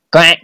match-start.wav